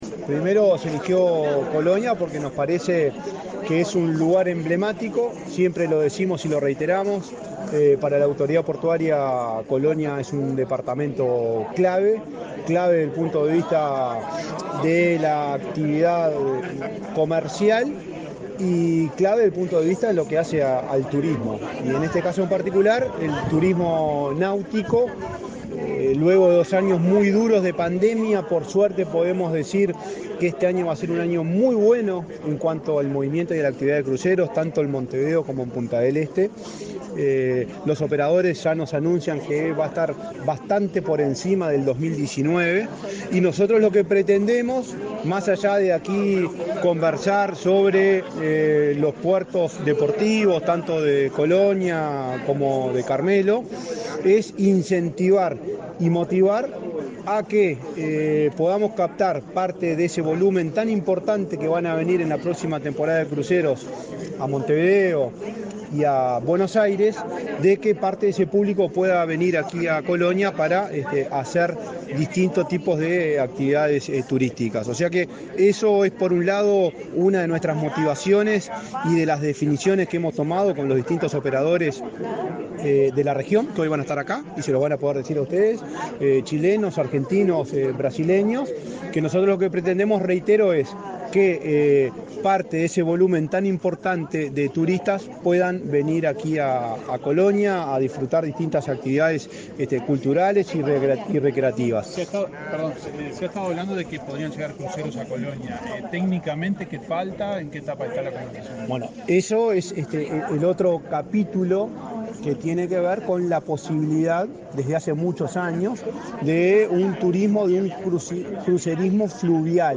Declaraciones a la prensa del presidente de la ANP, Juan Curbelo
Declaraciones a la prensa del presidente de la ANP, Juan Curbelo 13/10/2022 Compartir Facebook X Copiar enlace WhatsApp LinkedIn Tras participar en el lanzamiento del VIII Encuentro Regional de Cruceros y Turismo Náutico Fluvial, este 13 de octubre, en Colonia del Sacramento, el presidente de la Administración de Puertos (ANP), Juan Curbelo, efectuó declaraciones a la prensa.